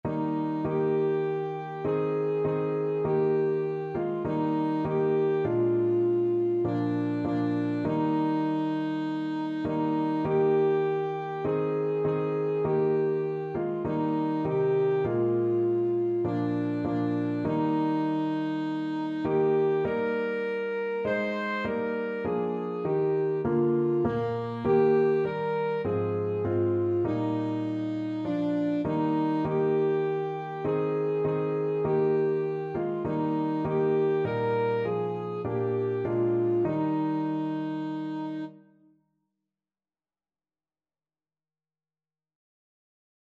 Alto Saxophone
4/4 (View more 4/4 Music)